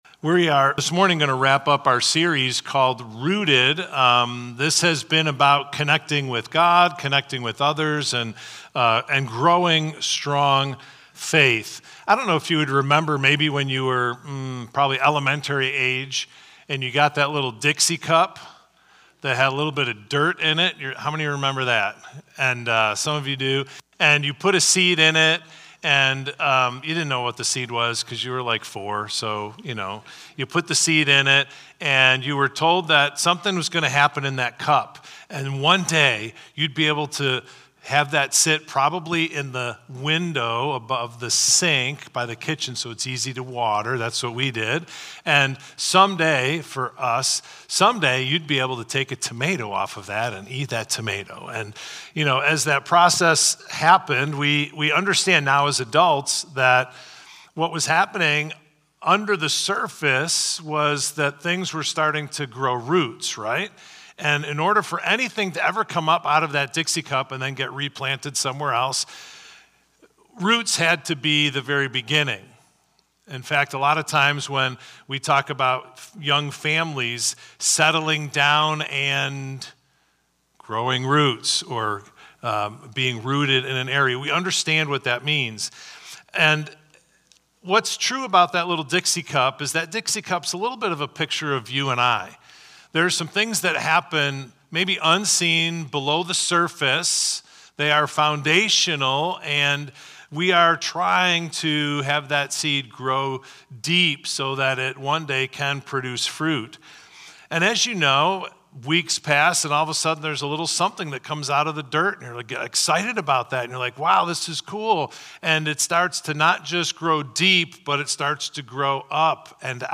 Victor Community Church Sunday Messages / Rooted: Why is the Church Important?